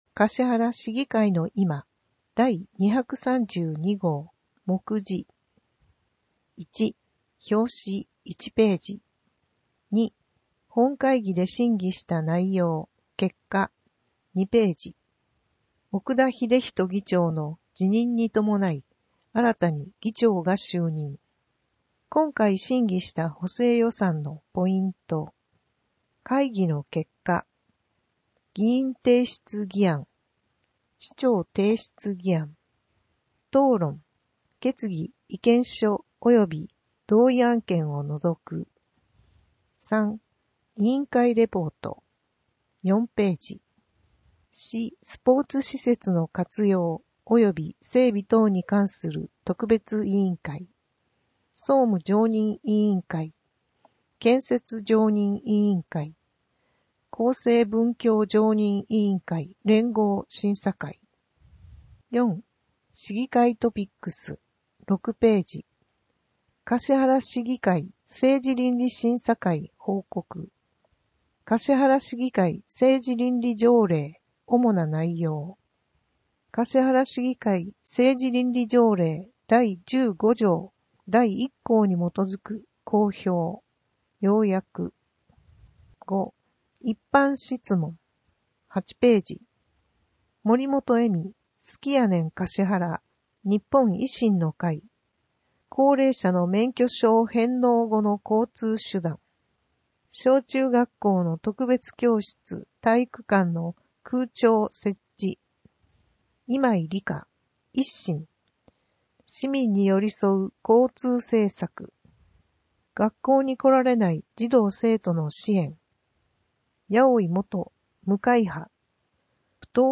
音訳データ